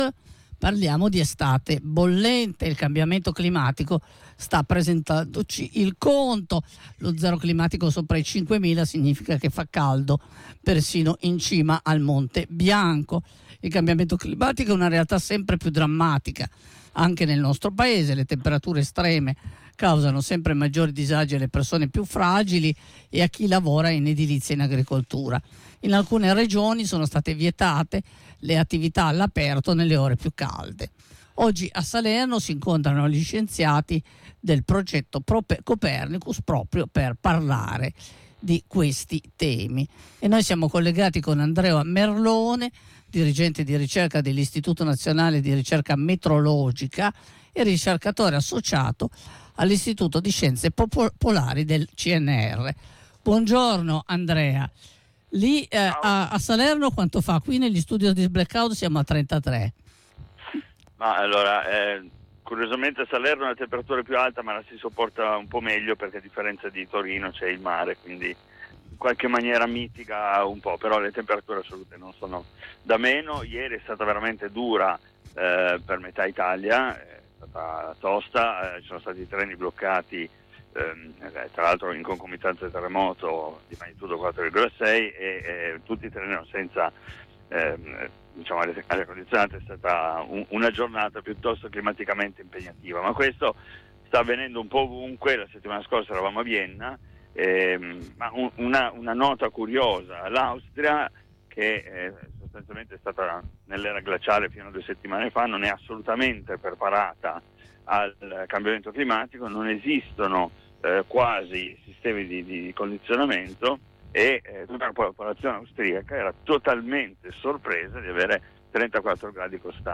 Ci siamo collegati con Salerno